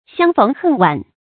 相逢恨晚 注音： ㄒㄧㄤ ㄈㄥˊ ㄏㄣˋ ㄨㄢˇ 讀音讀法： 意思解釋： 見「相見恨晚」。